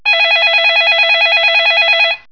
PHNRING.WAV